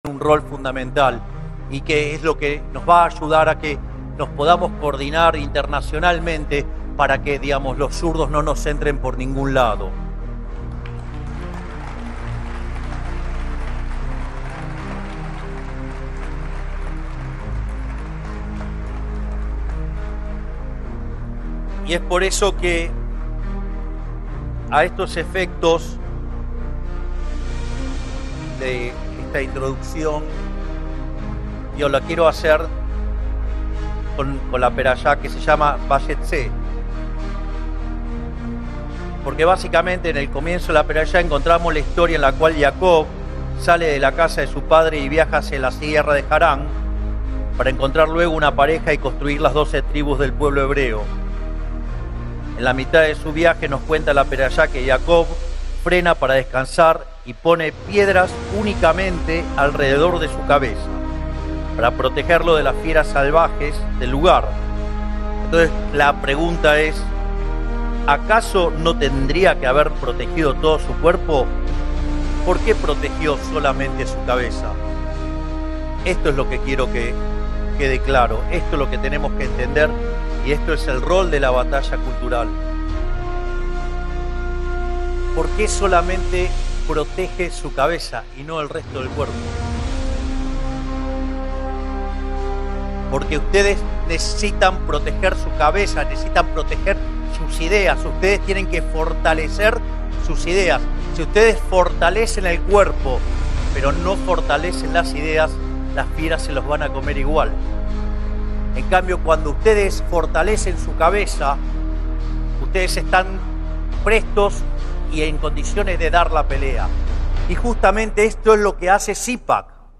חידוש בפרשת השבוע מפיו של נשיא ארגנטינה חאבייר מיליי...